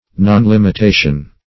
Nonlimitation \Non*lim`i*ta"tion\, n. Want of limitation; failure to limit.